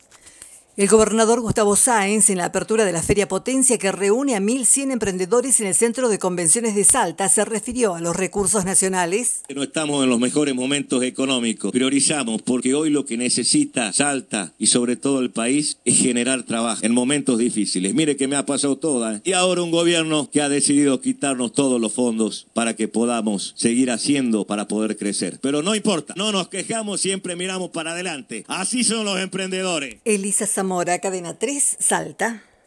El gobernador Gustavo Sainz inauguró la Feria Potencia en el centro de convenciones de Salta, un evento que reúne a 1.100 emprendedores. Durante su discurso, Sainz se refirió a la situación económica actual en el país.